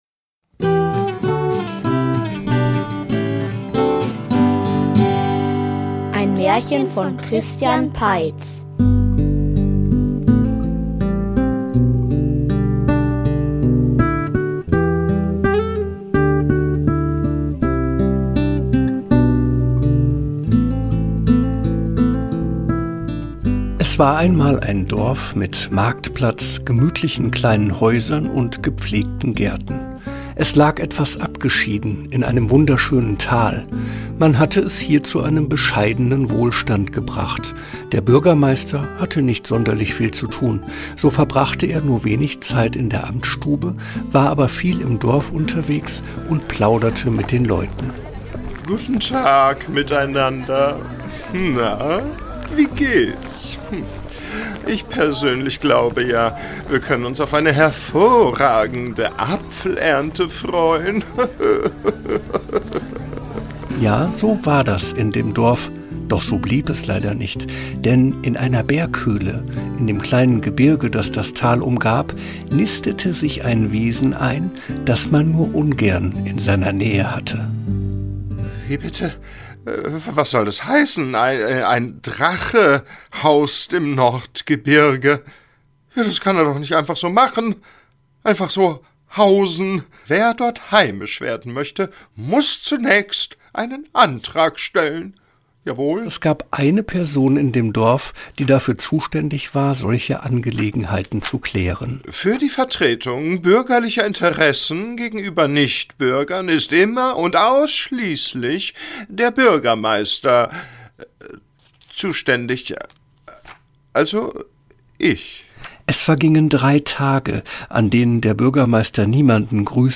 Download - Der Wettstreit um die schöne Florentine --- Märchenhörspiel #38 | Podbean